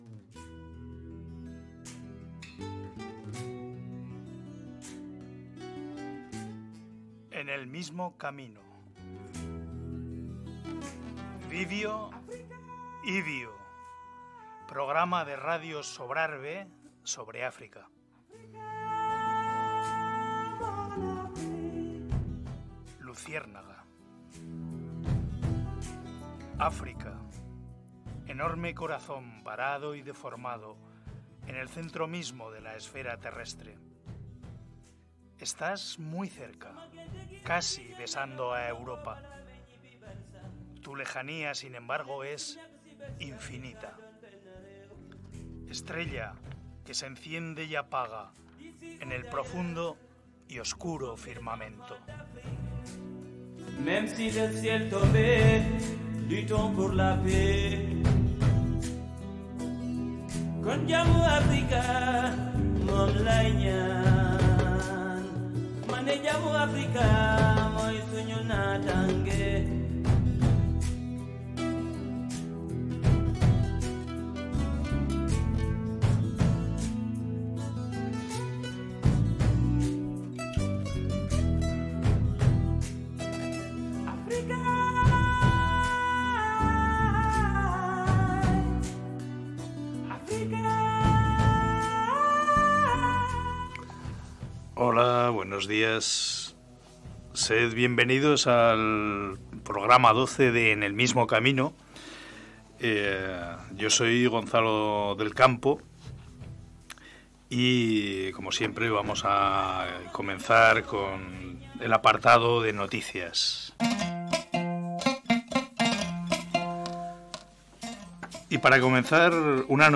Este es un programa de radio que tiene por objeto informar sobre África en todos sus aspectos, Política, Sociedad, Cultura, Música…